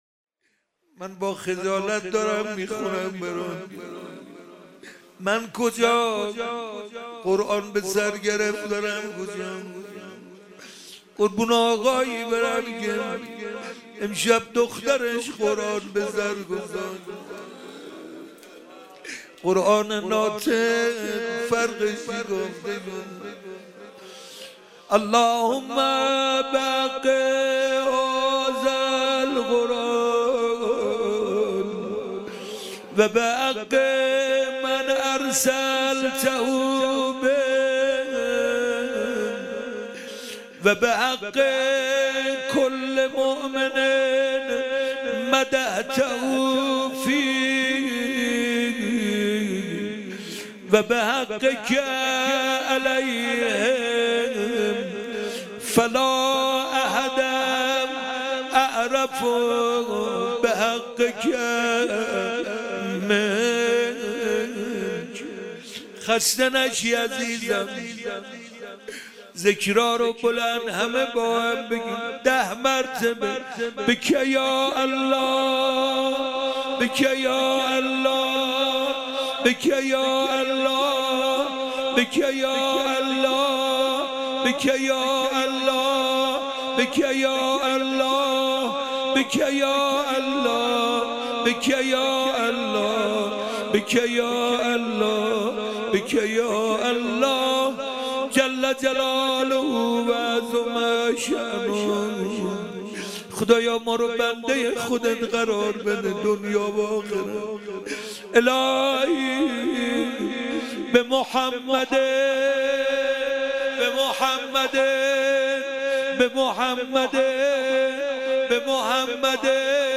مراسم احیا شب بیست و یکم